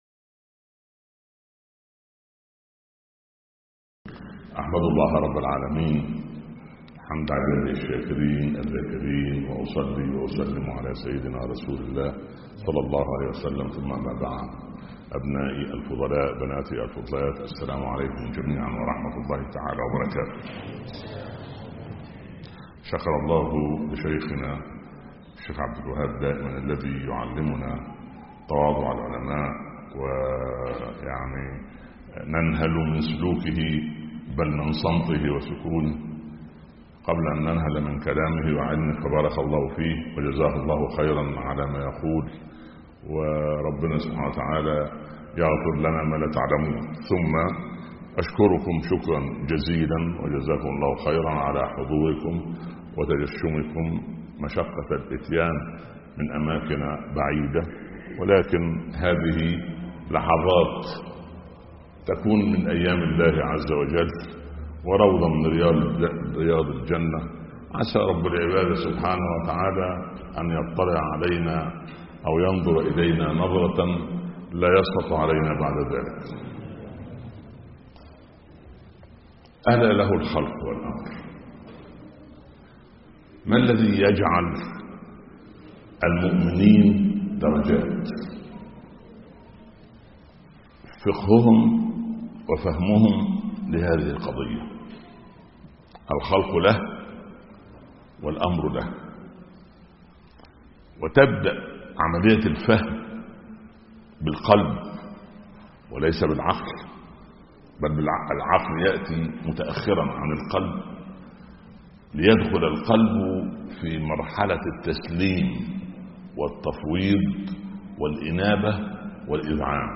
محاضرة تحوي إشارات ومقتطفات لتجدد وتعزز روح الإيمان في قلب العبد.